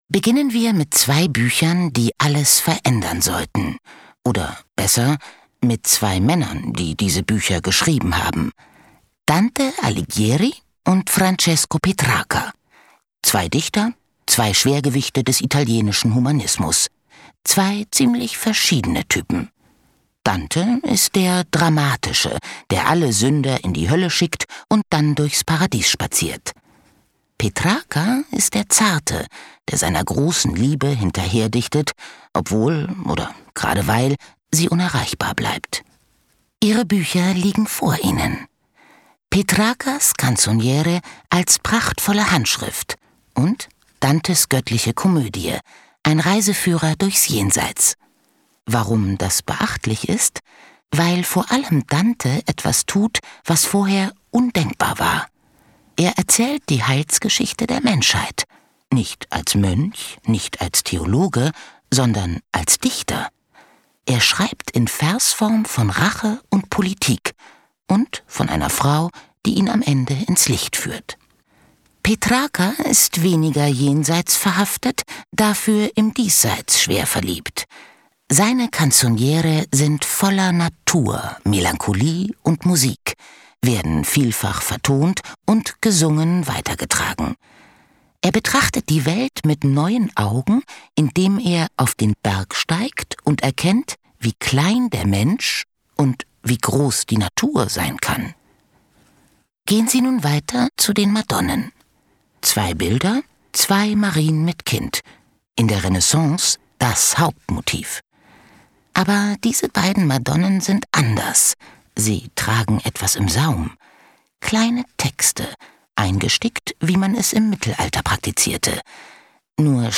Ausstellung »GÖTTLICH!« im DIMU Freising